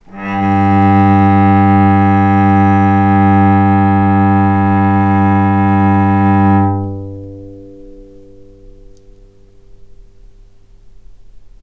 healing-soundscapes/Sound Banks/HSS_OP_Pack/Strings/cello/ord/vc-G2-mf.AIF at c8d0b62ab1b5b9a05c3925d3efb84e49ca54a7b3
vc-G2-mf.AIF